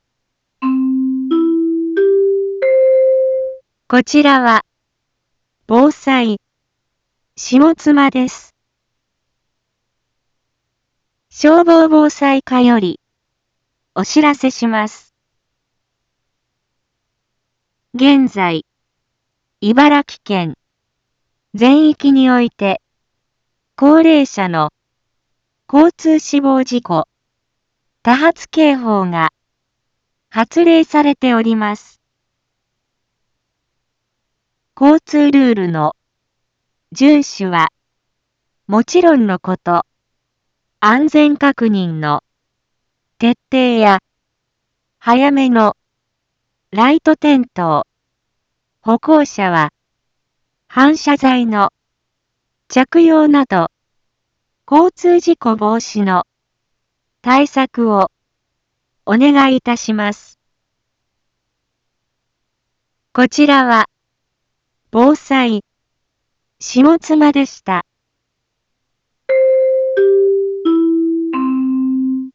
Back Home 一般放送情報 音声放送 再生 一般放送情報 登録日時：2023-09-05 17:31:15 タイトル：交通死亡事故多発警報(高齢者警報)発令 インフォメーション：こちらは、防災、下妻です。